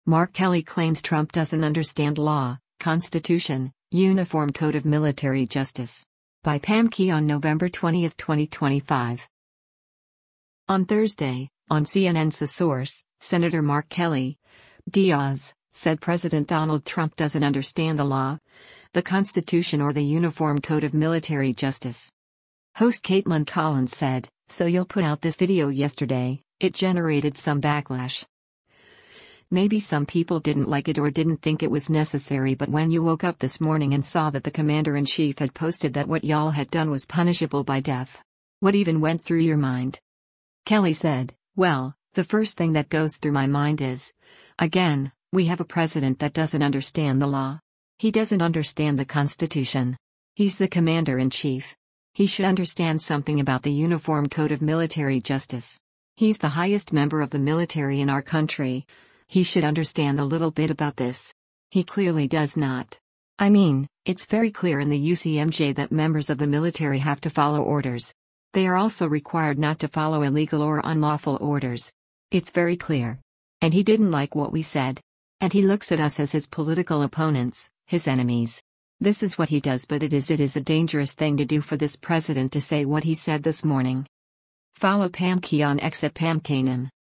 On Thursday, on CNN’s “The Source,” Sen. Mark Kelly (D-AZ) said President Donald Trump doesn’t understand the law, the Constitution or the Uniform Code of Military Justice.